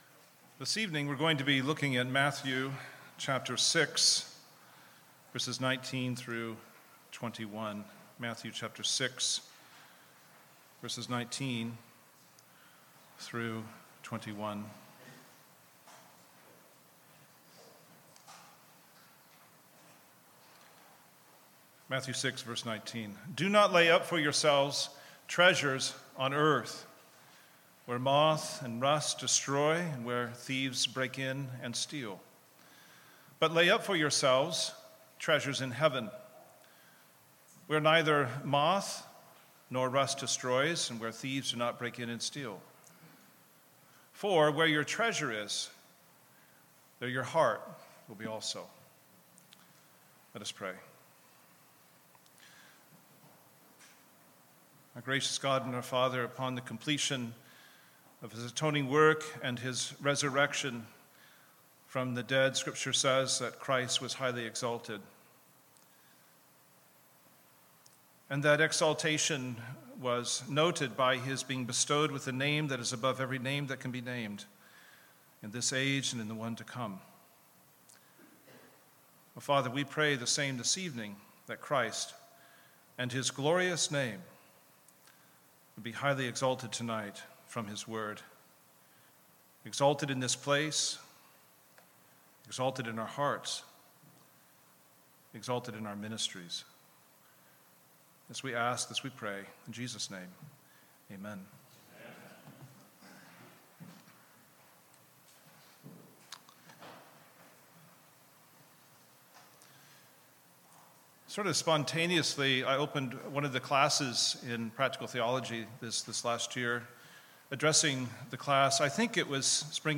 Ministry in Light of Eternity: Worship Service 2 – Our Heavenly Treasure